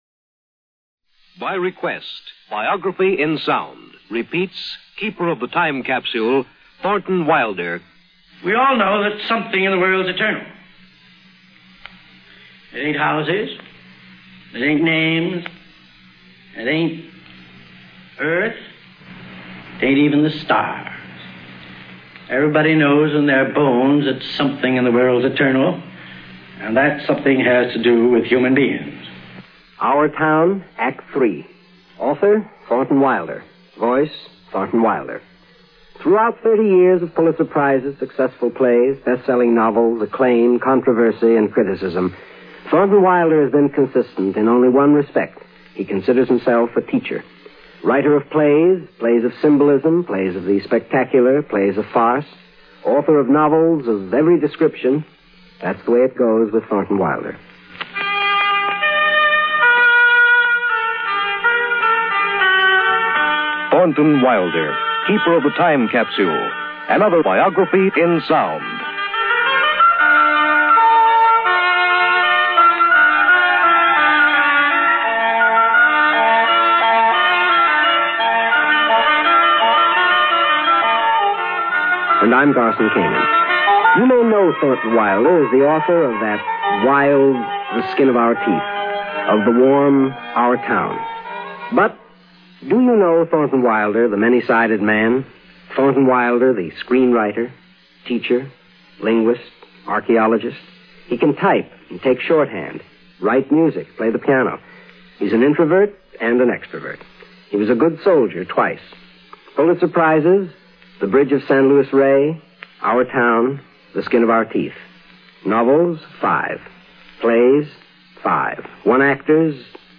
Featuring Garson Kanin narrator